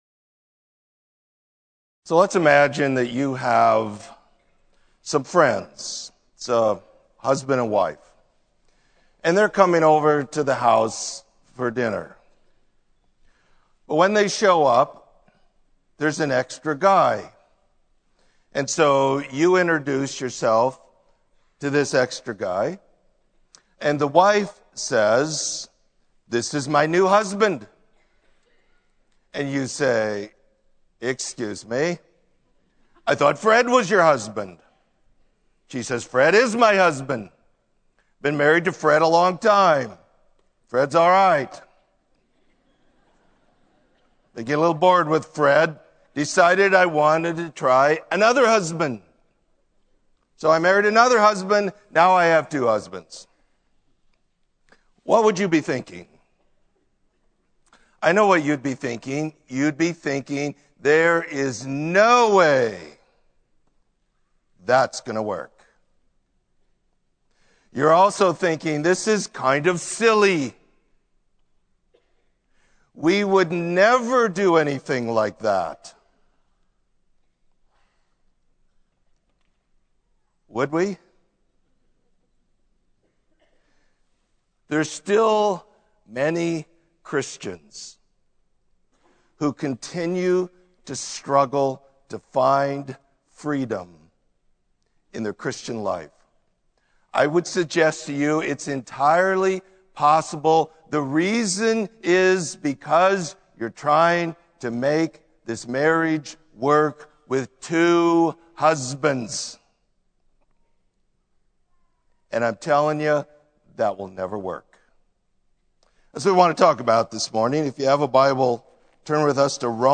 Sermon: Remarried